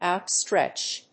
/aʊˈtstrɛtʃ(米国英語), aʊˈtstretʃ(英国英語)/